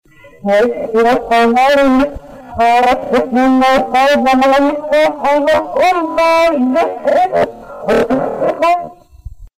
E la tristezza che lega le sue parole è davvero grande, e lei non riesce a nasconderla nemmeno dietro il tono "ruffiano" che usa per comunicare con noi.